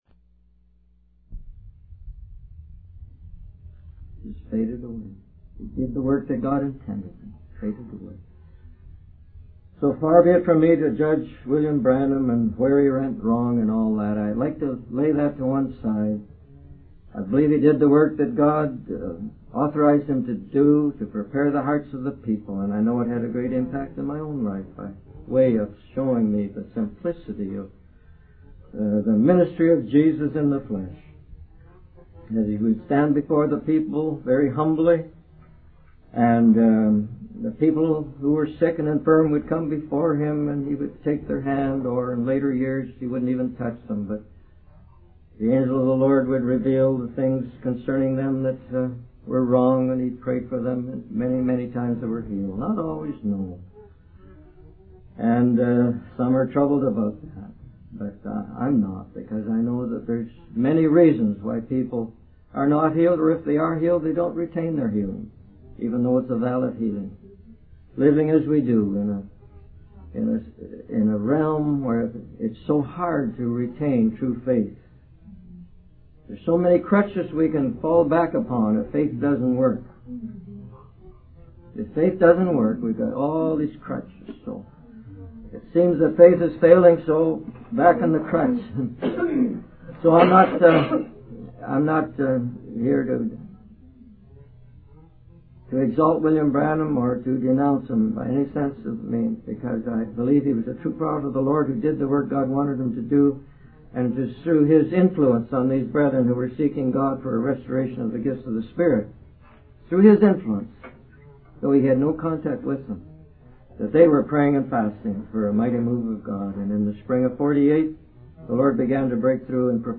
In this sermon, the speaker emphasizes the importance of following the direction and orders of the captain of the hosts of the Lord. He uses the example of Joshua receiving the blueprint for the conquest of Jericho, where God instructed the priests to take trumpets and the Ark of the Covenant to walk around the city instead of using swords.